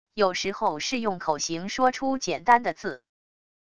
有时候是用口型说出简单的字wav音频生成系统WAV Audio Player